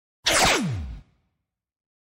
One Piece Whoosh Sound Effect Free Download
One Piece Whoosh